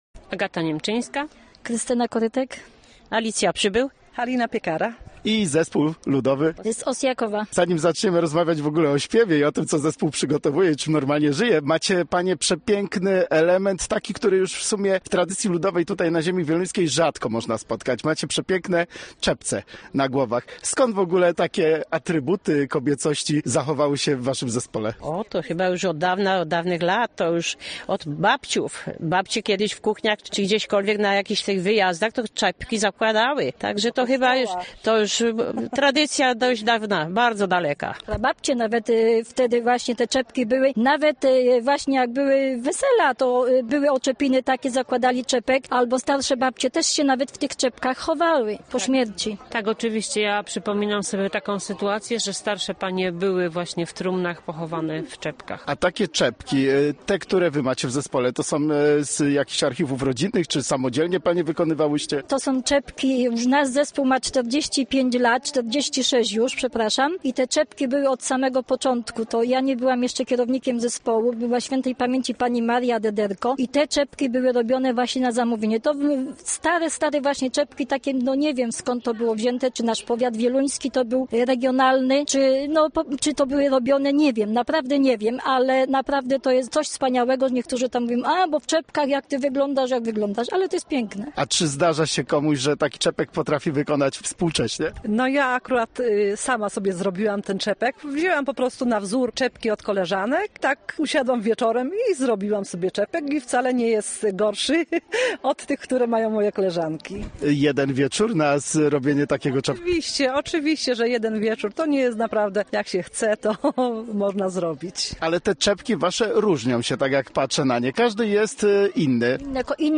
Nagranie zrealizowano podczas Międzypowiatowego Przeglądu Zespołów Śpiewaczych Ostrówek 2022 (6-7 maja 2022).